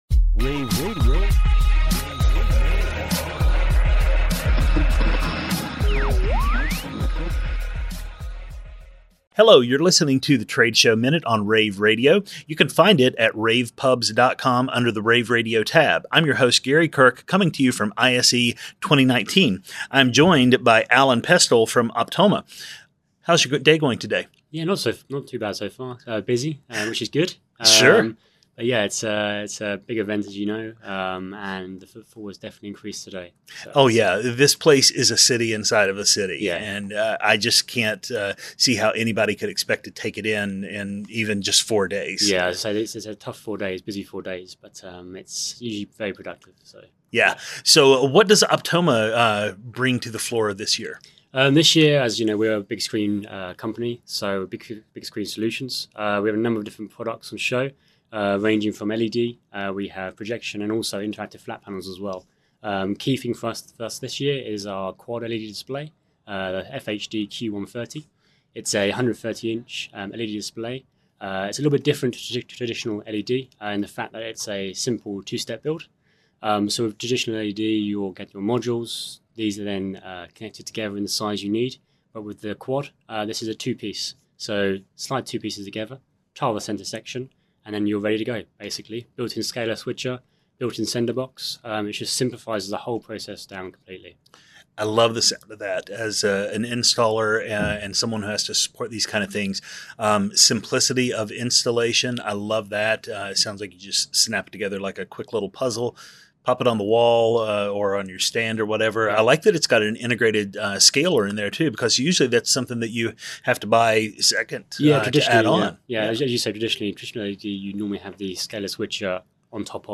interviews
February 6, 2019 - ISE, ISE Radio, Radio, rAVe [PUBS], The Trade Show Minute,